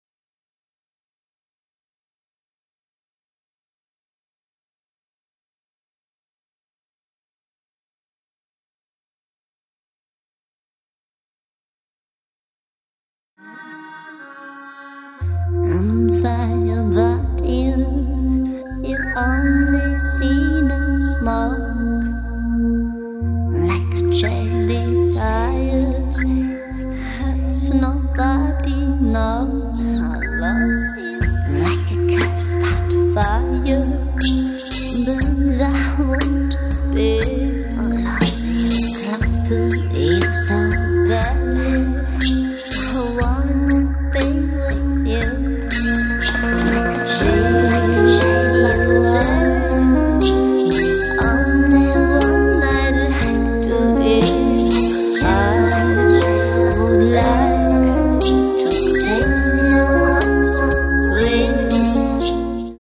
keyboards
drums
bass
guitars